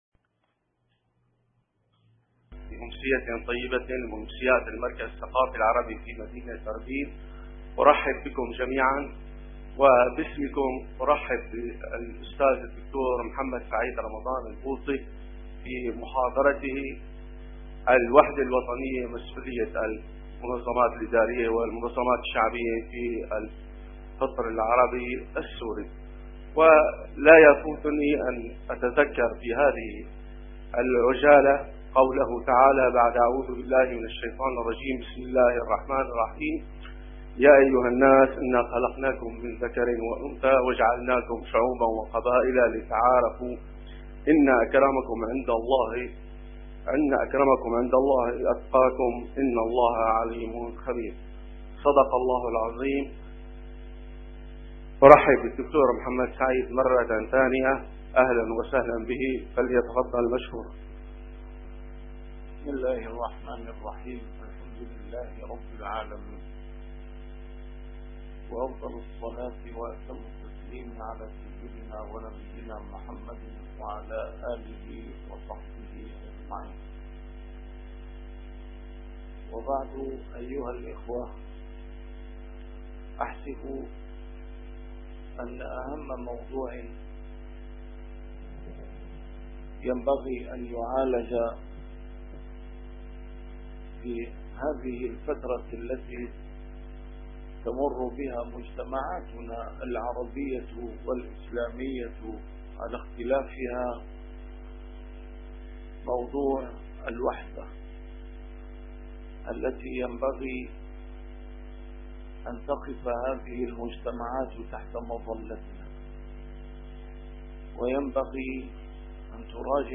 نسيم الشام › A MARTYR SCHOLAR: IMAM MUHAMMAD SAEED RAMADAN AL-BOUTI - الدروس العلمية - محاضرات متفرقة في مناسبات مختلفة - محاضرة للعلامة الشهيد عن الوحدة الوطنية في المركز الثقافي في عربين